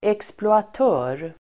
Ladda ner uttalet
Uttal: [eksploat'ö:r]